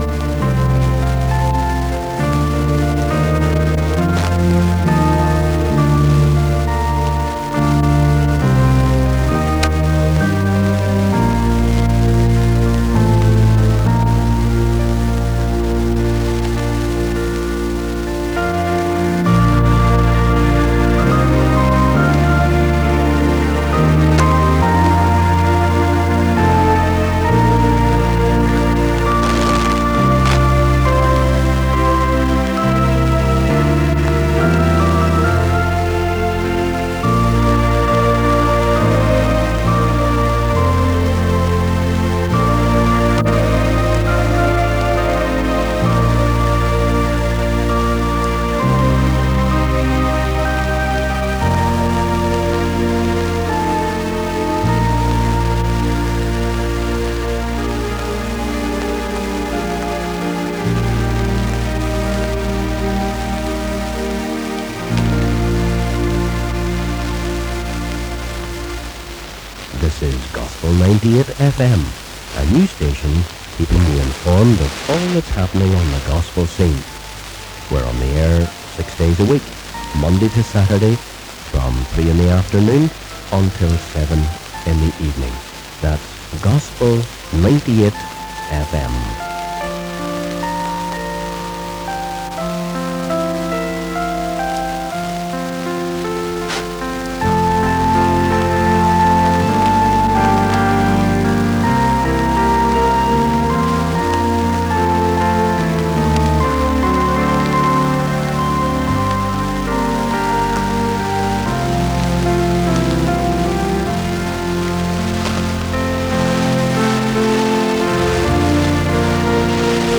This short recording is of a test transmission the day before Gospel 98 went on air and features music interspersed with recorded messages. The station was to broadcast from Monday to Saturday from 1500-1900 and promised gospel music and ‘lively content’.
Audio quality is fair and characterised by fading as to be expected some distance from the transmitter.